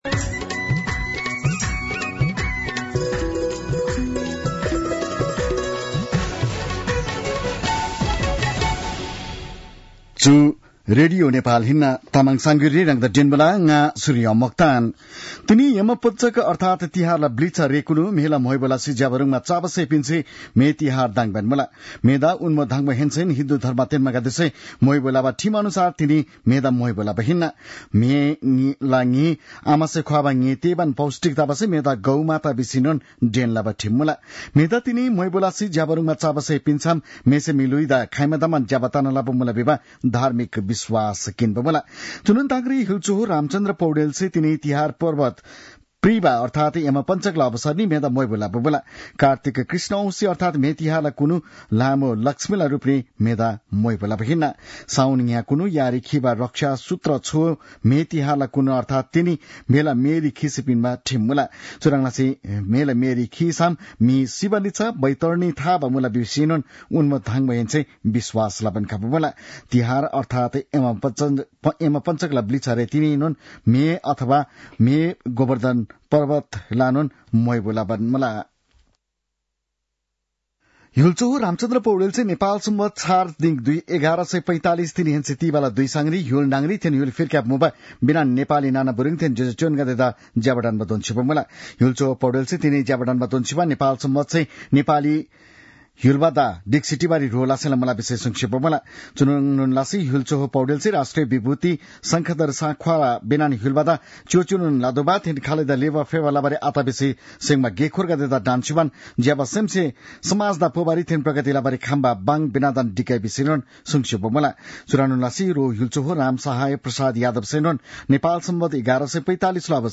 तामाङ भाषाको समाचार : १८ कार्तिक , २०८१